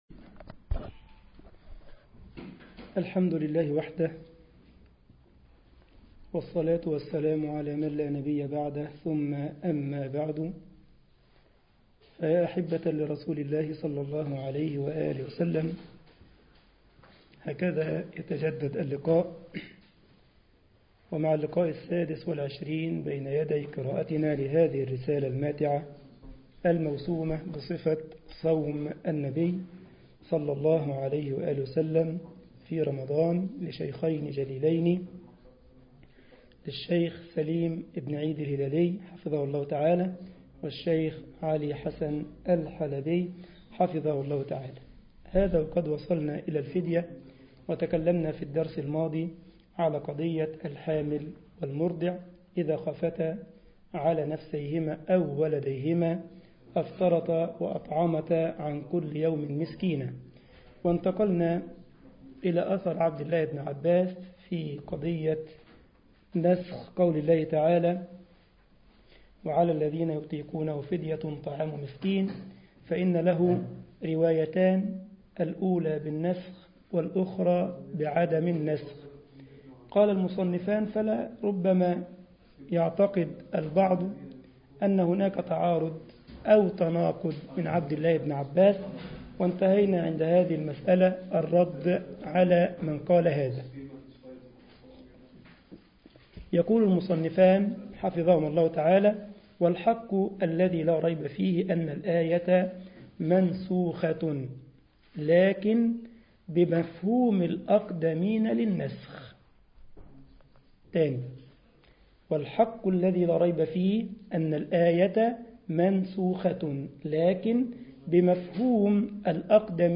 مسجد الجمعية الاسلامية بالسارلند المانيا